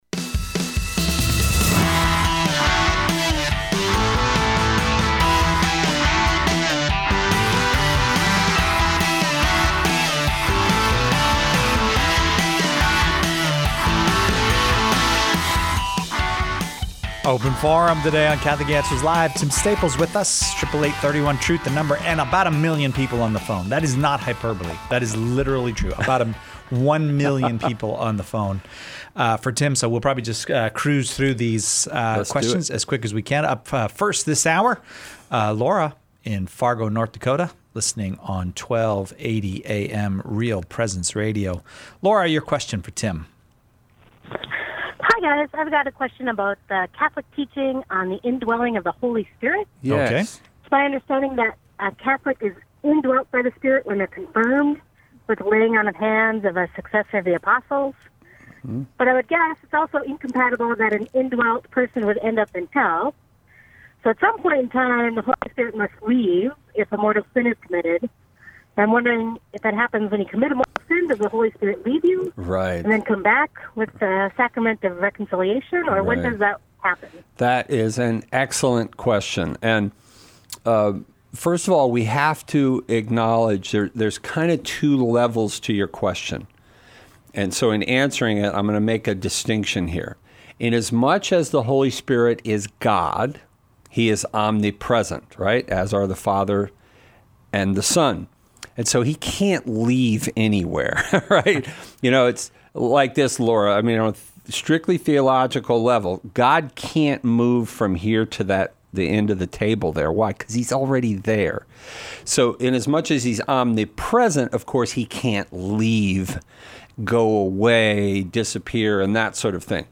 What is the Catholic belief in the Holy Spirit? Is the Rapture going to happen? Callers choose the topics during Open Forum, peppering our guests with questions on every aspect of Catholic life and faith, the moral life, and even philosophical topics that touch on general religious belief.